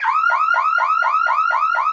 flag_alert.wav